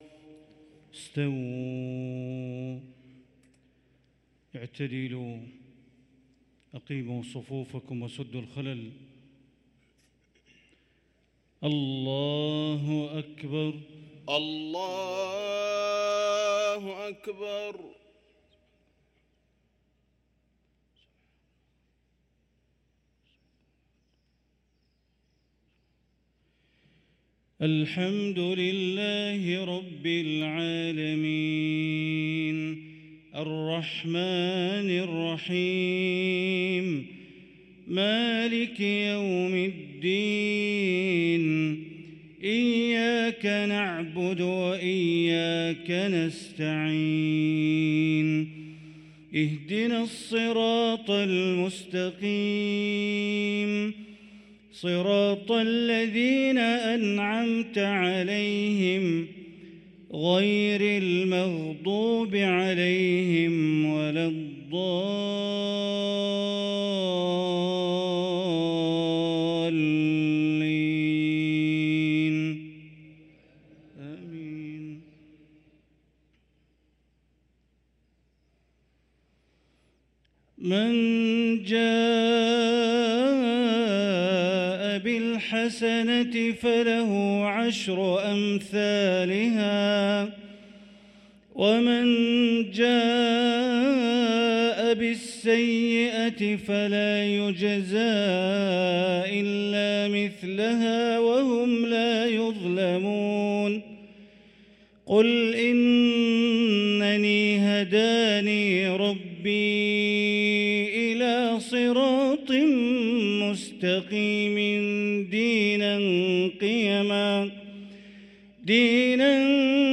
صلاة المغرب للقارئ بندر بليلة 27 صفر 1445 هـ